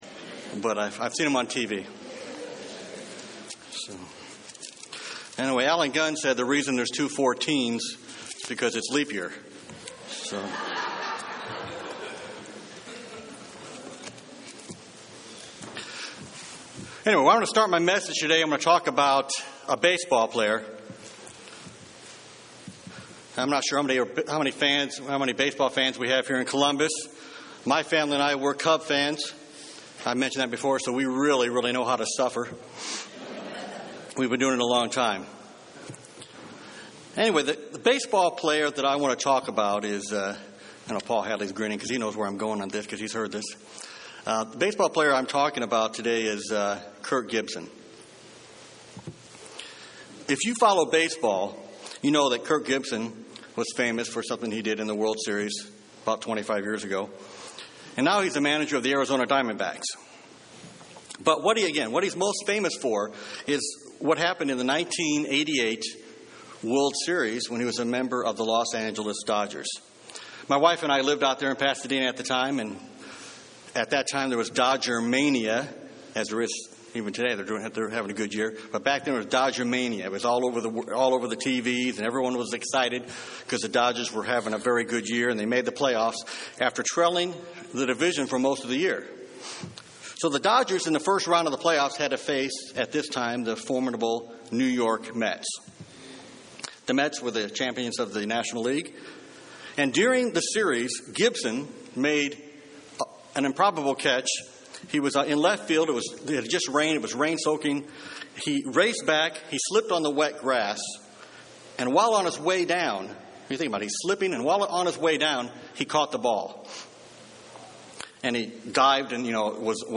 Given in Columbus, OH
UCG Sermon